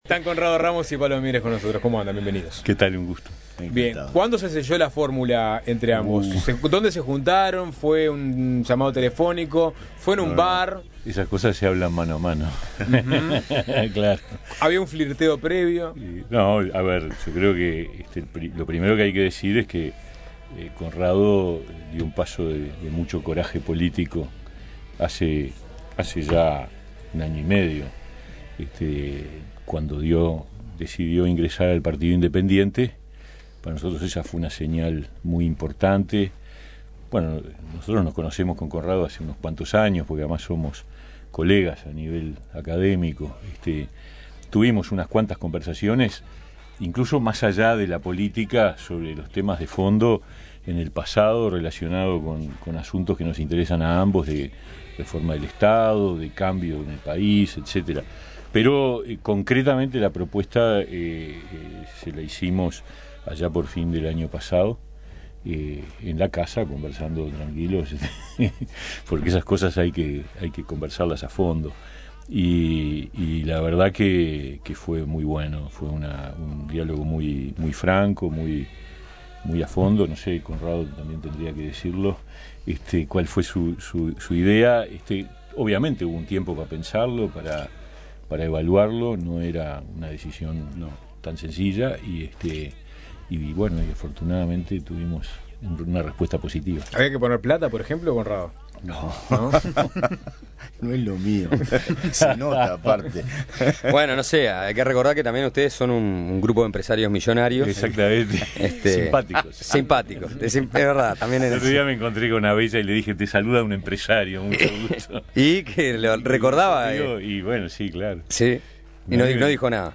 La fórmula presidencial del Partido Independiente conversó con nosotros sobre todo: desde cuál es la política de seguridad efectiva y no demagógica hasta cuál es el animal que representa mejor al PI.